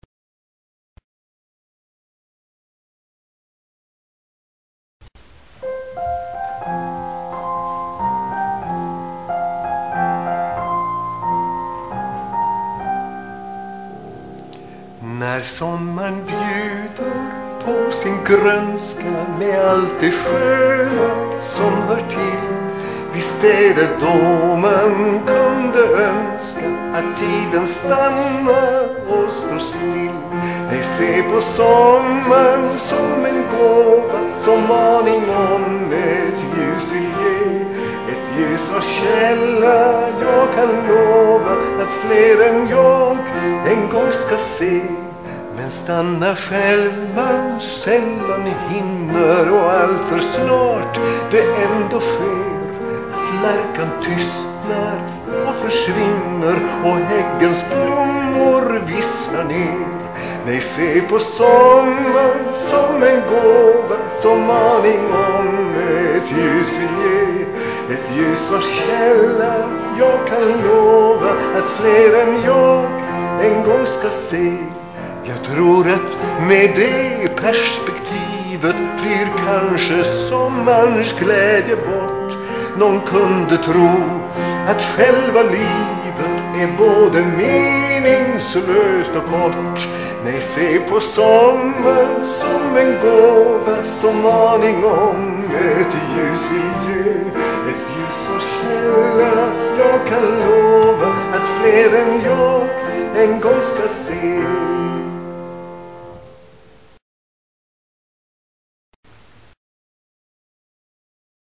Det rör sig om inspelningar med sång till eget pianoackompanjemang.
Jag ber den som lyssnar notera att alla visorna är tagna ”live”.